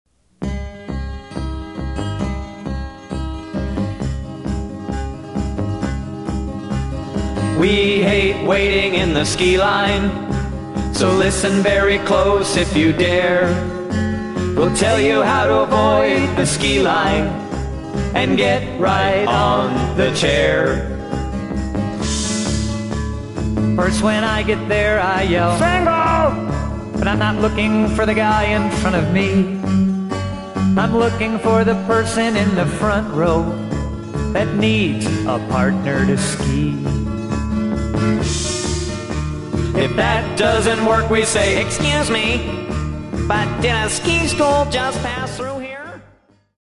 DIGITALLY RE-MASTERED FOR BETTER SOUND!